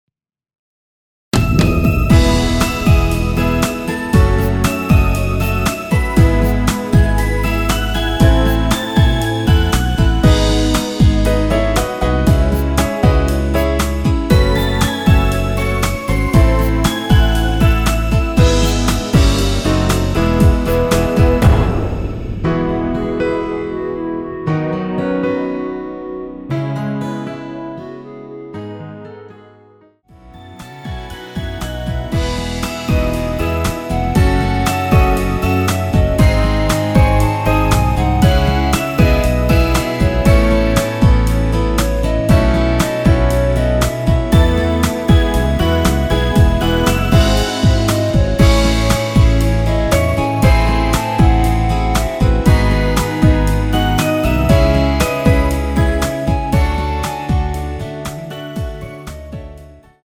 원키에서(+1)올린 멜로디 포함된 1절후 바로 후렴부분으로 진행되게 편곡 하였습니다.
◈ 곡명 옆 (-1)은 반음 내림, (+1)은 반음 올림 입니다.
앞부분30초, 뒷부분30초씩 편집해서 올려 드리고 있습니다.